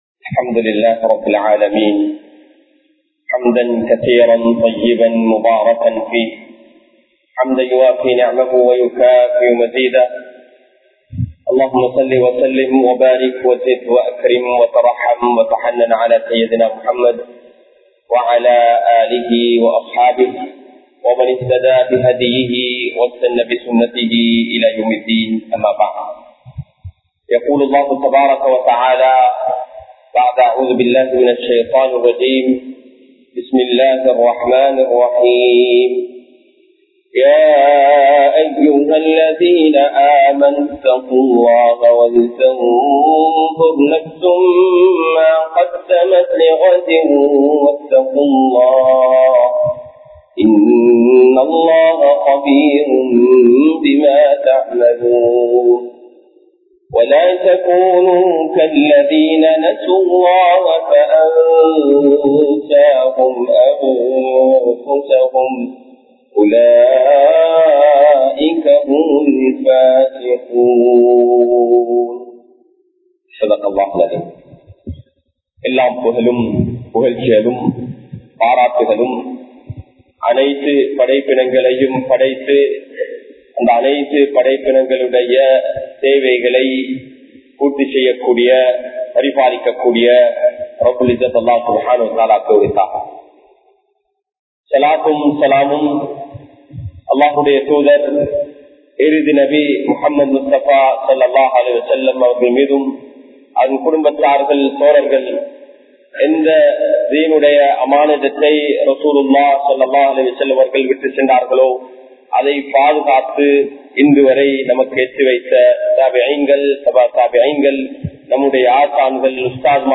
உண்மையான முஸ்லிமாக வாழ்வோம் | Audio Bayans | All Ceylon Muslim Youth Community | Addalaichenai
Muhiyadeen Jumua Masjidh